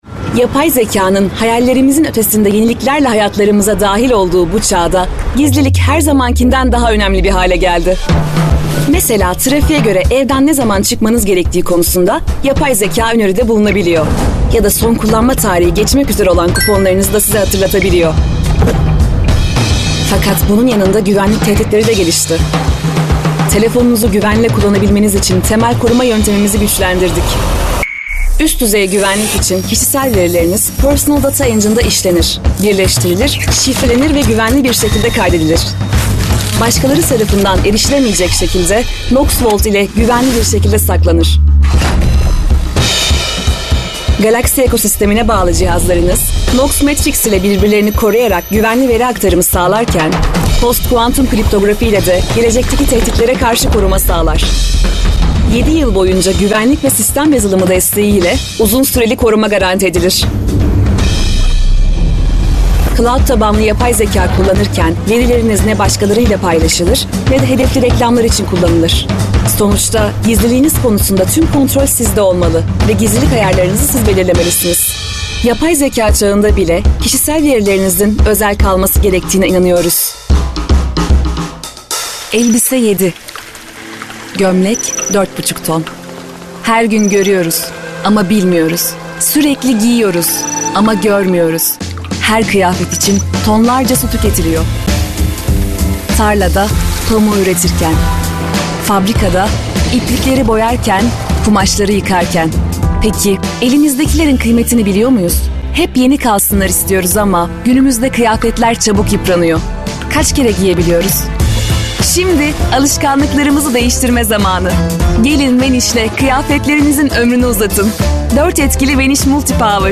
KATEGORİ Kadın
Canlı, Eğlenceli, Fragman, Güvenilir, Havalı, Karakter, IVR, Seksi, Karizmatik, Promosyon, Sıcakkanlı, Film Sesi, Tok / Kalın, Genç, Dış Ses,